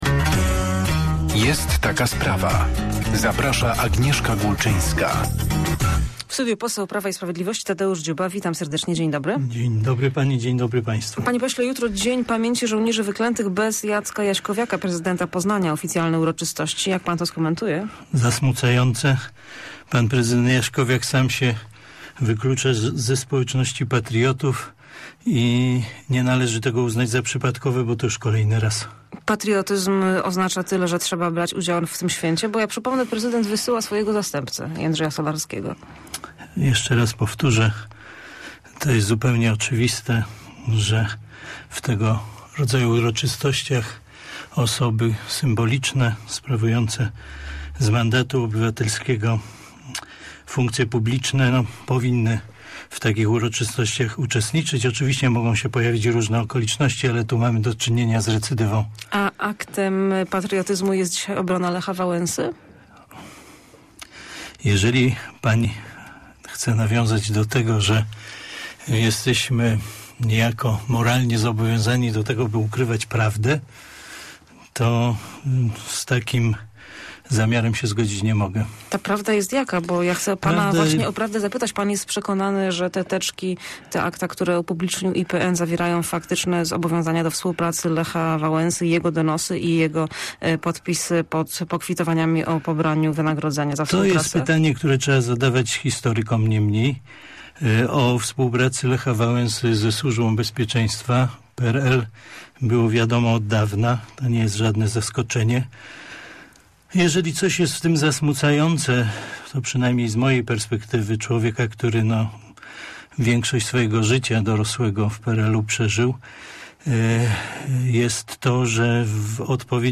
Poseł PiS Tadeusz Dziuba w porannej rozmowie Radia Merkury skrytykował nieobecność prezydenta Poznania na wtorkowych uroczystościach z okazji Dnia Pamięci Żołnierzy Wyklętych.